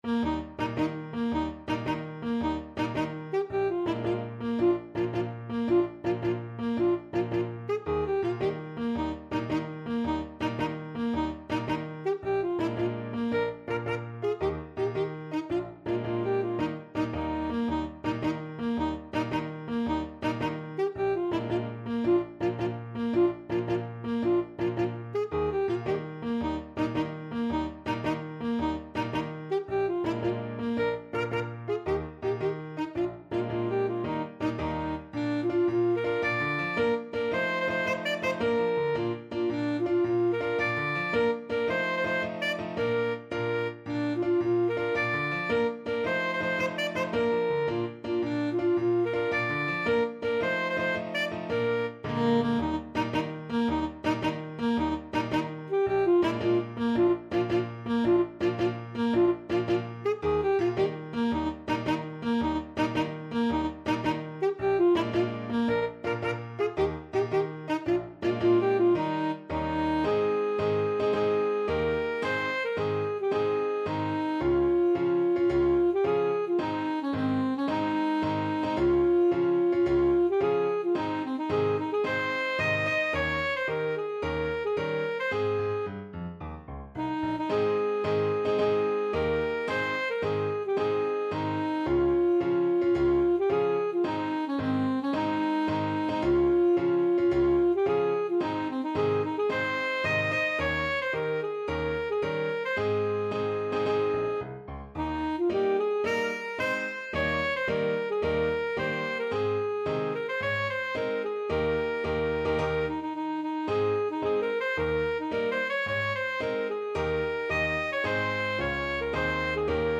Play (or use space bar on your keyboard) Pause Music Playalong - Piano Accompaniment Playalong Band Accompaniment not yet available transpose reset tempo print settings full screen
Alto Saxophone
6/8 (View more 6/8 Music)
Eb major (Sounding Pitch) C major (Alto Saxophone in Eb) (View more Eb major Music for Saxophone )
Classical (View more Classical Saxophone Music)